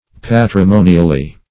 patrimonially - definition of patrimonially - synonyms, pronunciation, spelling from Free Dictionary Search Result for " patrimonially" : The Collaborative International Dictionary of English v.0.48: Patrimonially \Pat`ri*mo"ni*al*ly\, adv. By inheritance.